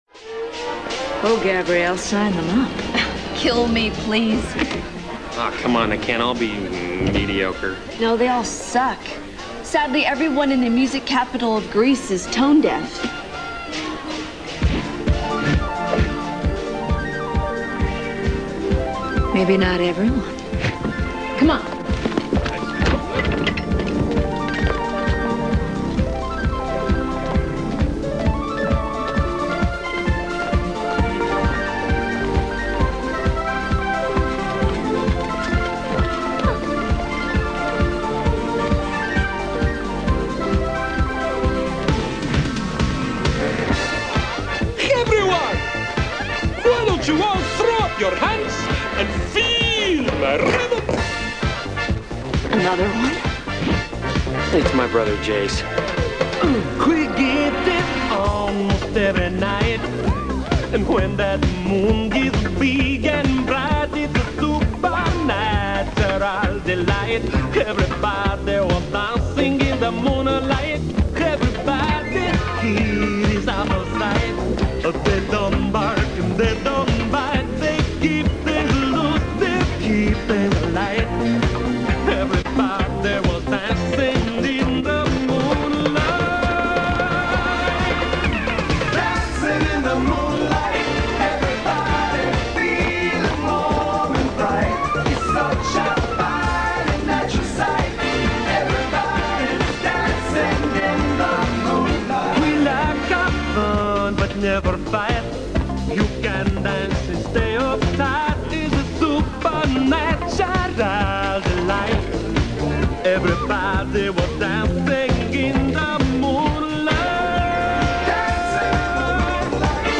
Все песни записаны с видео, без русского перевода.